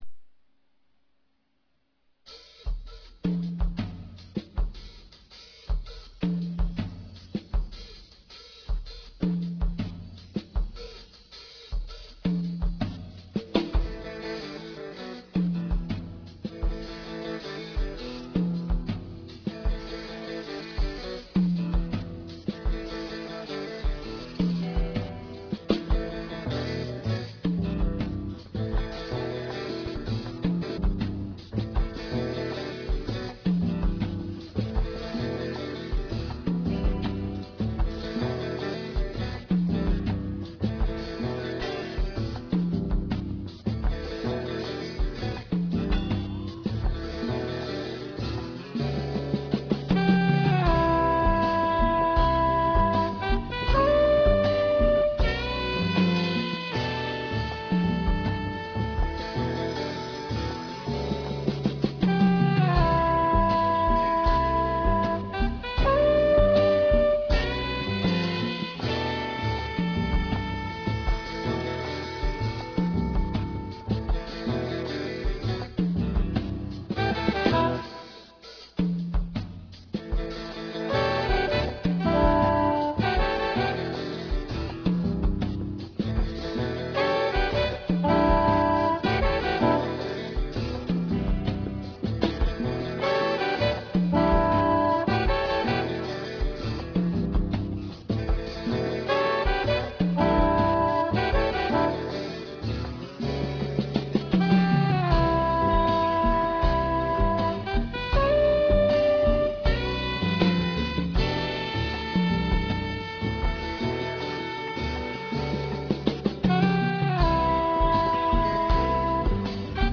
Drums
Tenor and Soprano Saxophone
Guitar
Bass
Percussion